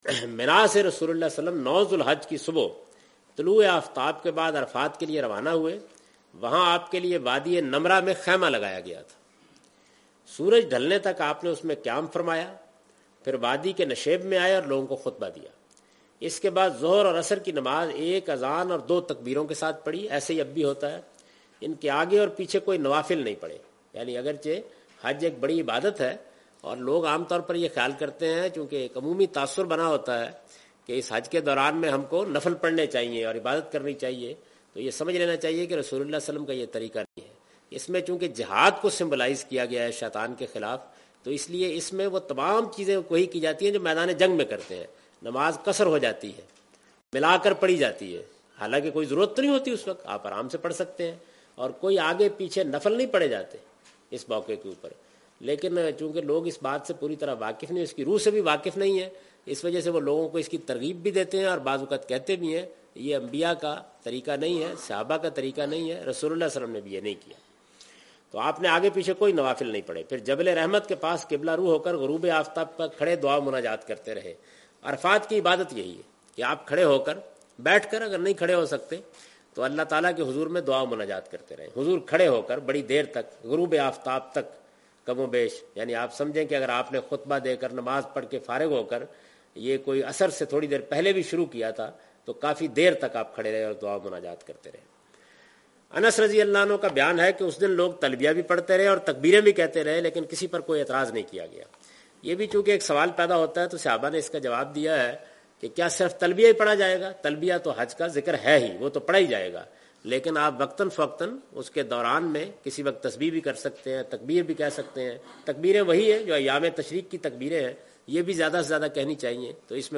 In this video of Hajj and Umrah, Javed Ahmed Ghamdi is talking about "Wuqoof Arafaat".
حج و عمرہ کی اس ویڈیو میں جناب جاوید احمد صاحب غامدی "وقوفِ عرفات" سے متعلق گفتگو کر رہے ہیں۔